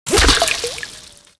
impactwatersmall02.wav